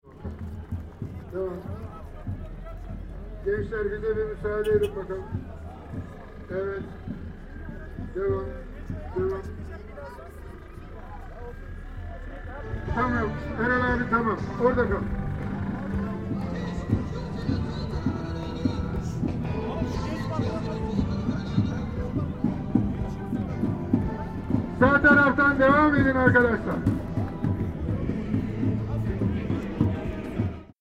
Parking the van
A man on the roof with a megaphone is telling the driver which way to go so and the people next by to give way. The sound of his voice is in sharp contrast with all the enthusiastic voices soon to be heard from the loudspeakers near the main stage.